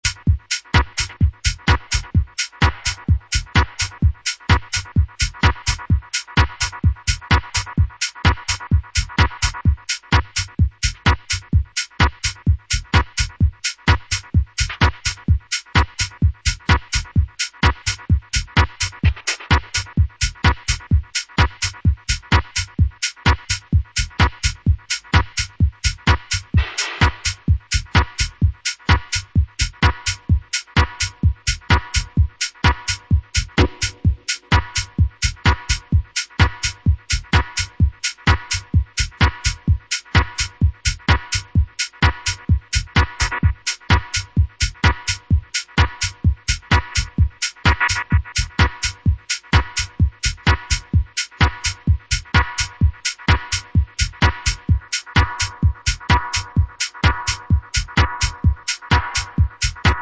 club tracks